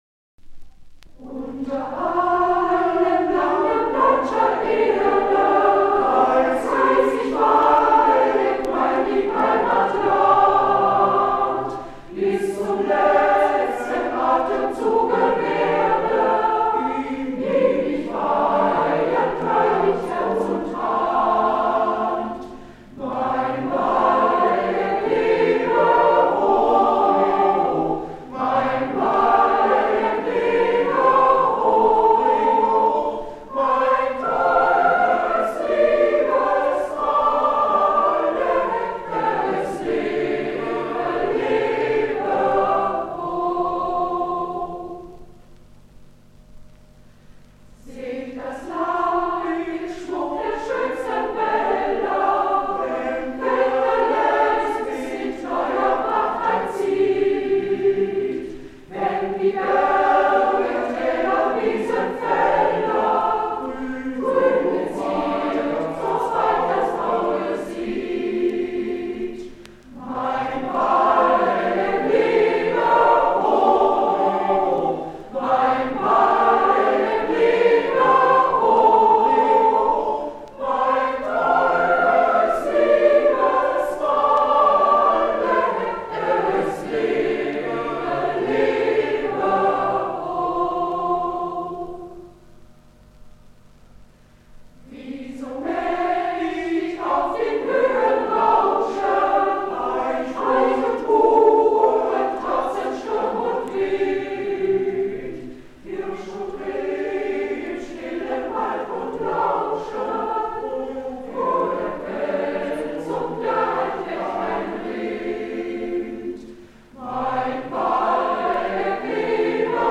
Waldecker Lied, gemischter Chor Bad Wildungen (1965)